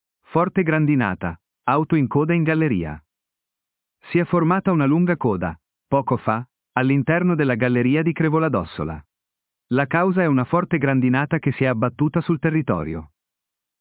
Forte grandinata, auto in coda in galleria
Si è formata una lunga coda, poco fa, all’interno della galleria di Crevoladossola. La causa è una forte grandinata che si è abbattuta sul territorio.